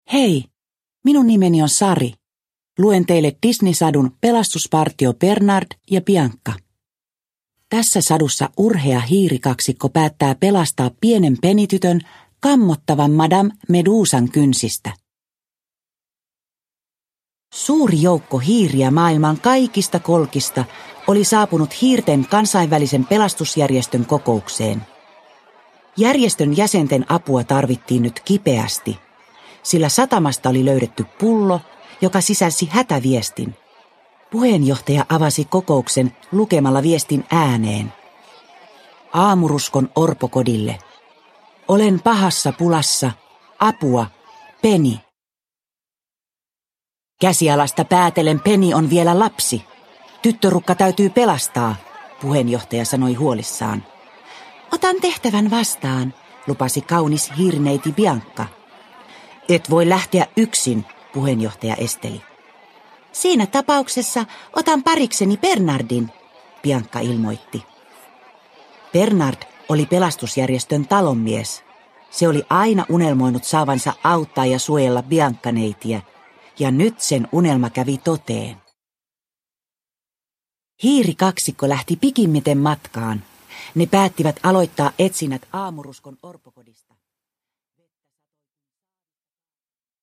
Pelastuspartio Bernard ja Bianca – Ljudbok – Laddas ner